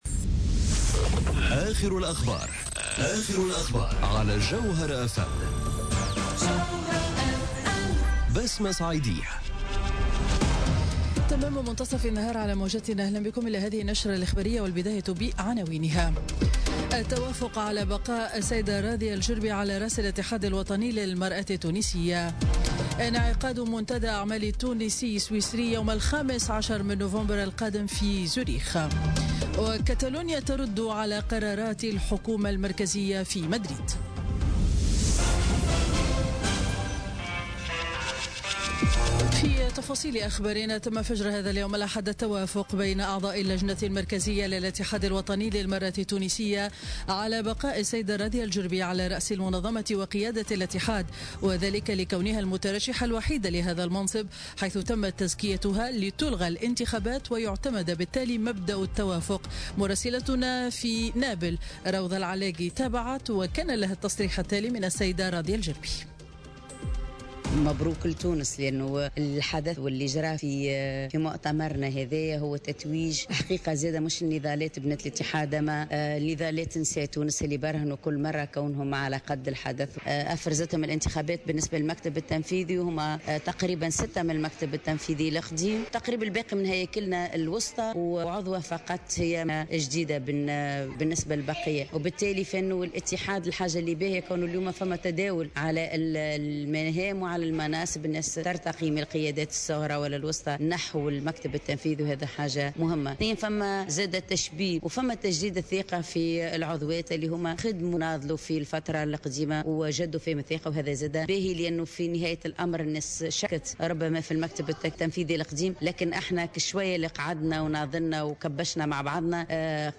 Journal Info 12h00 du dimanche 29 Octobre 2017